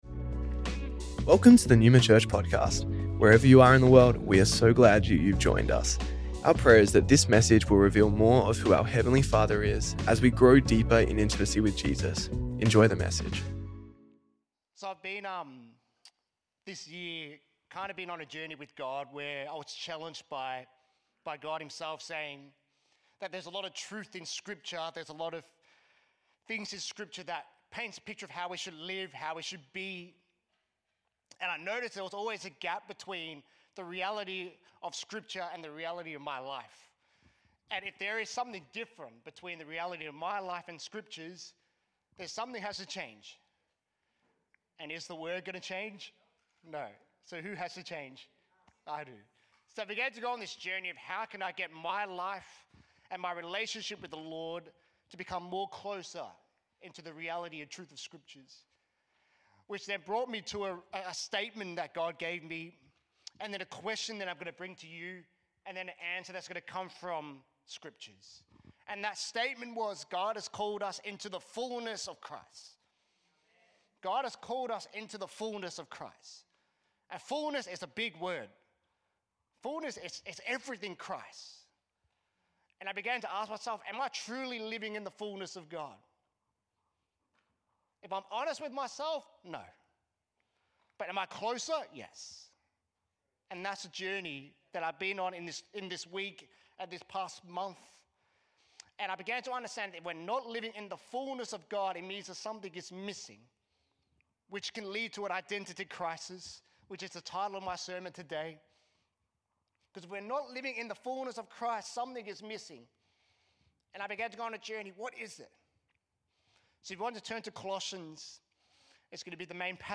Neuma Church Melbourne South Originally recorded at the 10AM Service on Sunday 4th May 2025